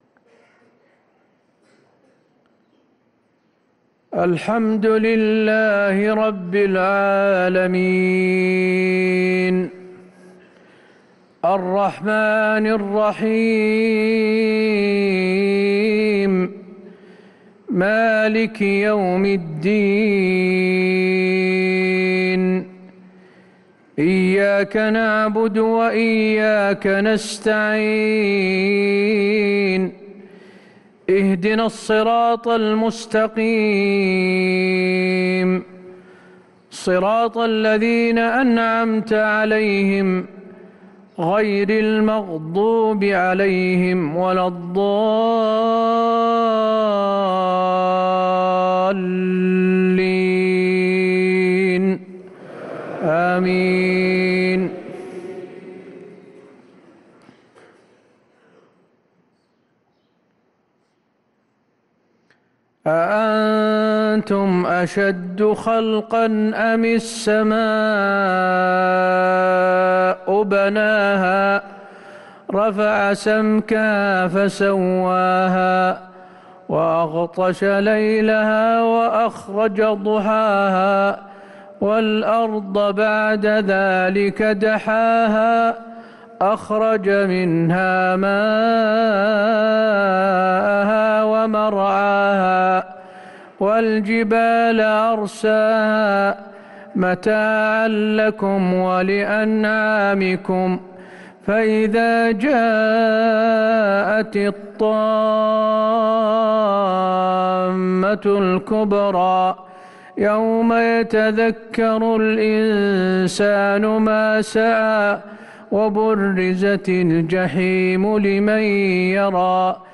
صلاة المغرب للقارئ حسين آل الشيخ 27 جمادي الأول 1445 هـ
تِلَاوَات الْحَرَمَيْن .